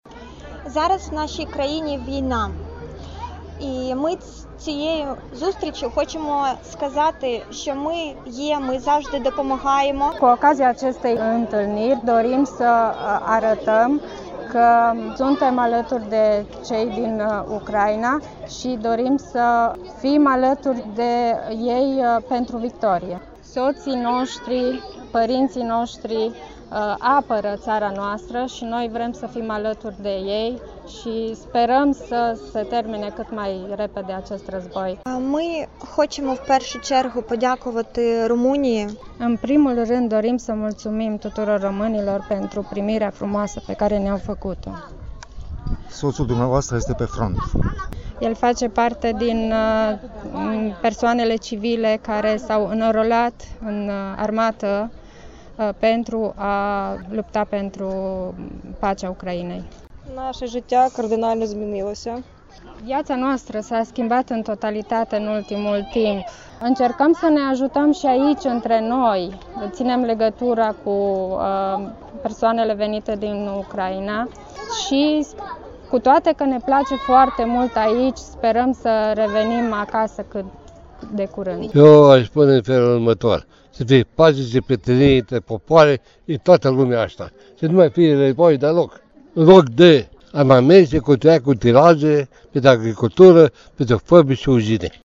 Protest al refugiaților ucraineni din Timișoara
VOX-uri-protest.mp3